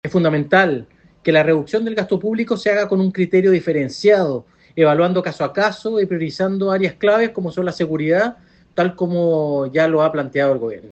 En tanto, el jefe de bancada republicano, Renzo Trisotti, llamó a evaluar la situación “caso a caso”.